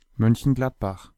Mönchengladbach pronounced in German (native speaker)
De-Mönchengladbach.ogg.mp3